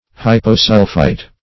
hyposulphite - definition of hyposulphite - synonyms, pronunciation, spelling from Free Dictionary
Hyposulphite \Hy`po*sul"phite\, n. (Chem.)